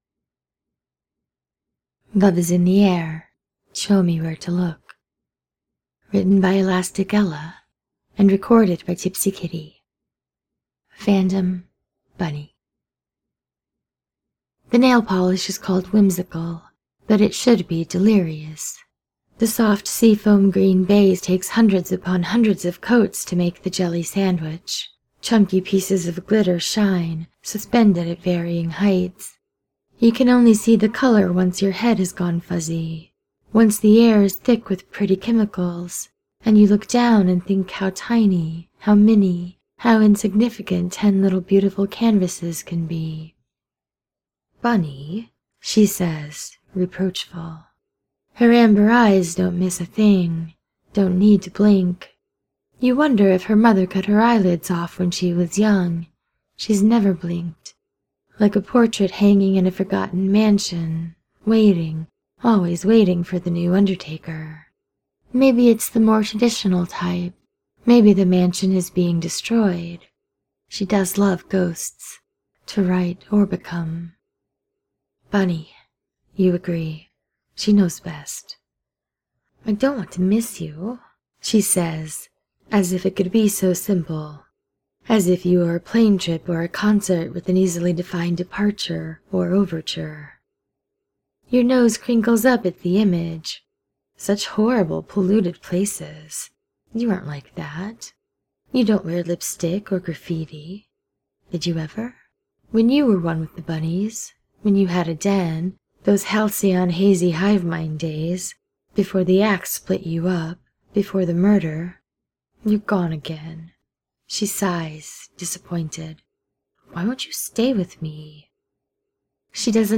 without music: